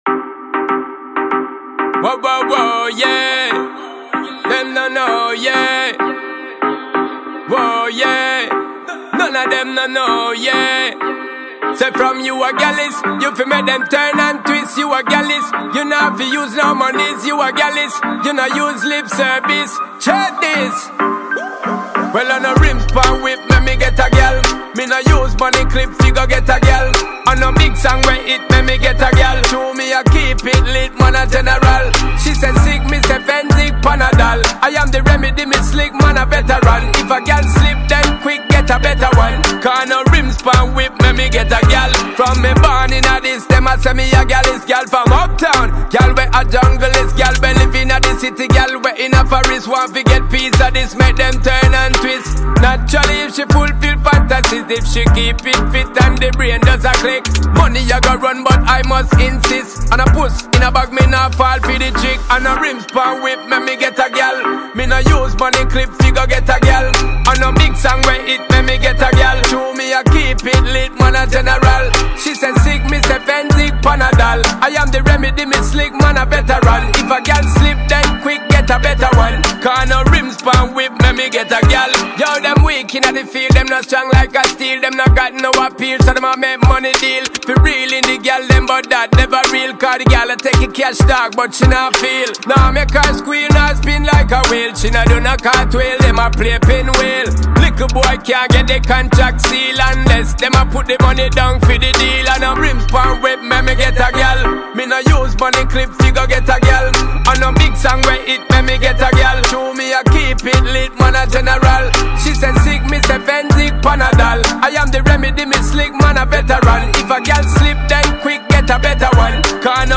Dancehall/HiphopMusic